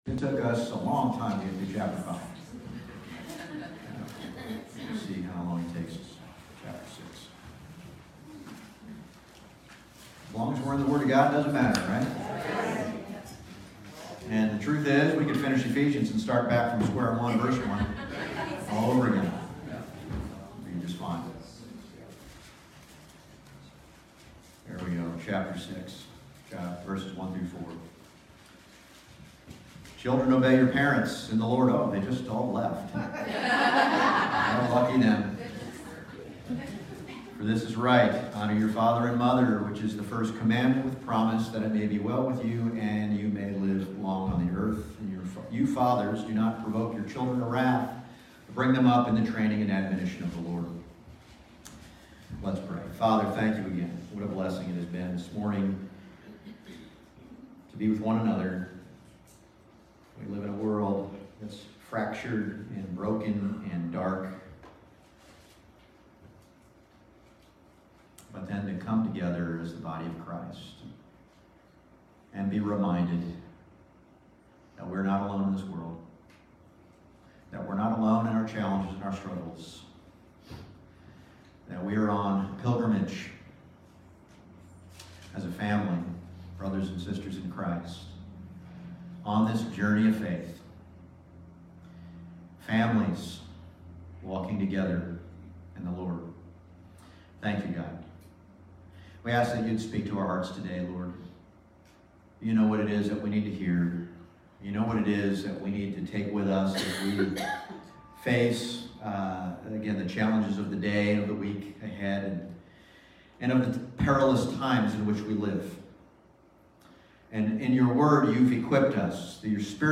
Ephesians 6:1-4 Service Type: Sunday Morning « The Spirit-Filled Church